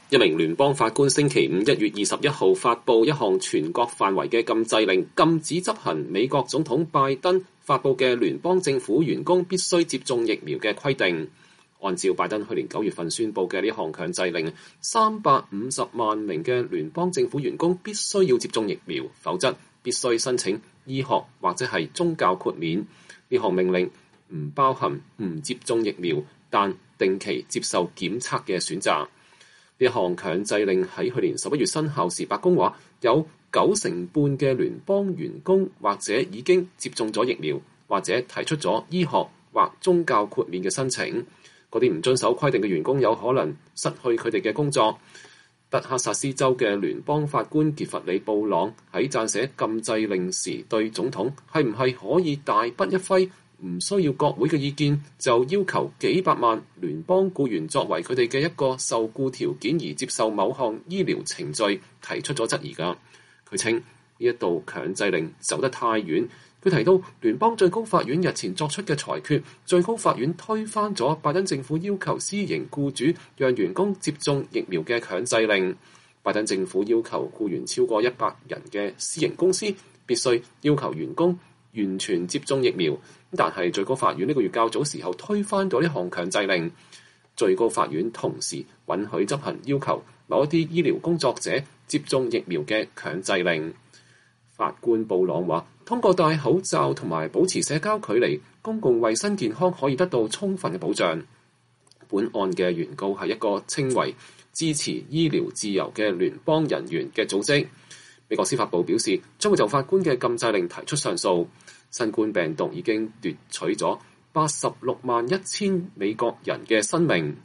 拜登總統在參觀了伊利諾伊州一處微軟數據中心建設工地後談接種新冠疫苗。